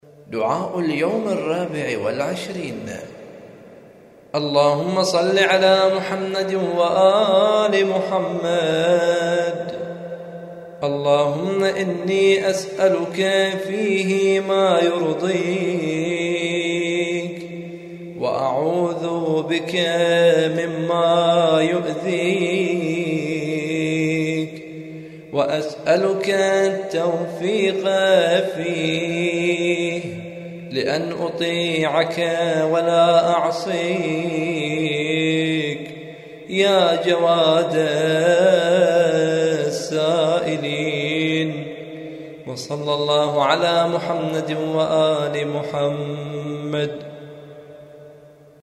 دعاء اليوم الرابع والعشرين من شهر رمضان